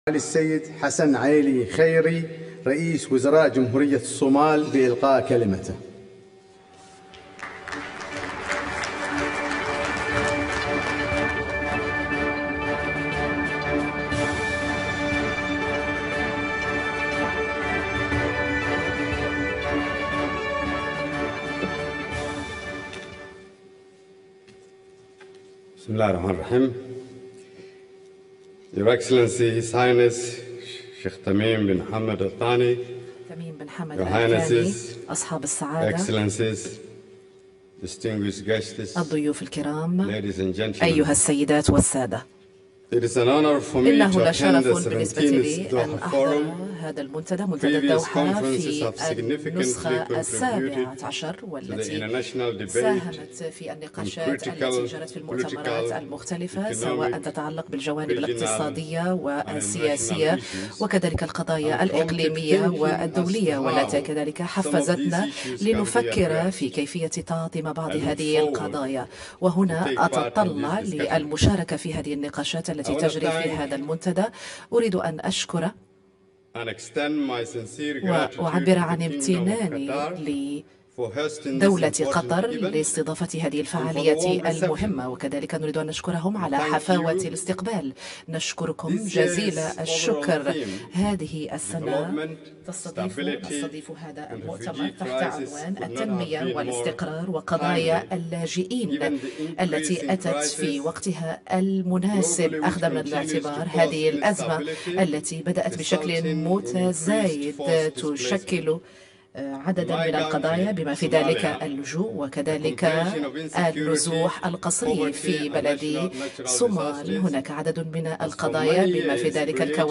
Dhagayso khudbadda uu R/wasaare Khayre ka jeediyay Shirka Doxa (Sawiro) | Goobsan Media Inc
DHAGEYSO-R-wasaaraha-oo-khudbad-ka-jeediyay-shirka-Dooxa-“SAWIRRO”-.mp3